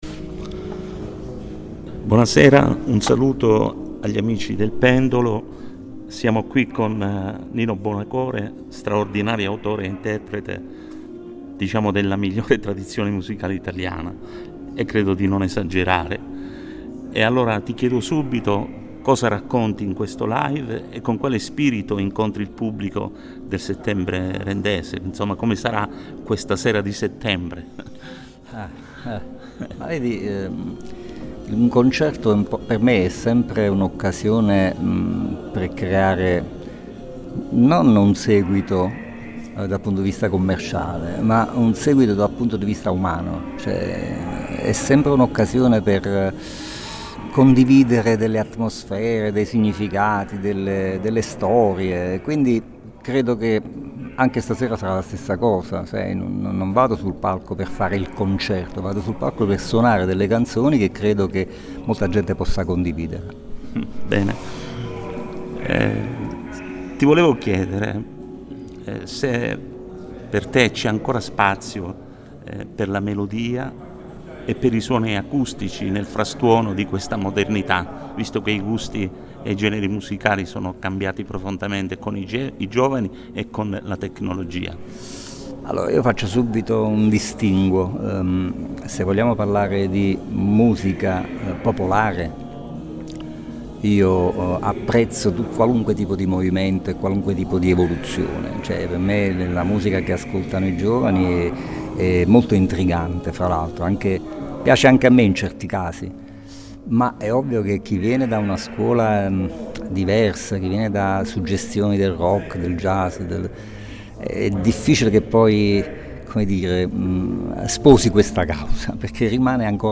Lo abbiamo incontrato nel corso del soundcheck prima del suo concerto acustico al Palazzetto dello Sport di Rende, nell’ambito dell’evento culturale Settembre Rendese
Intervista-a-Buonocore.mp3